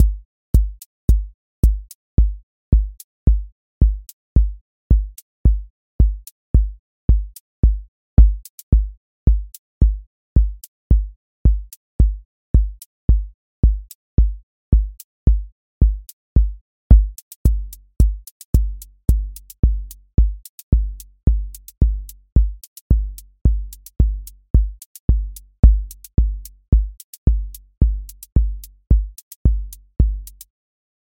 QA Listening Test house Template: four_on_floor
steady house groove with lift return
• voice_kick_808
• voice_hat_rimshot
• voice_sub_pulse